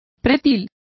Complete with pronunciation of the translation of parapet.